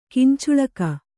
♪ kincuḷaka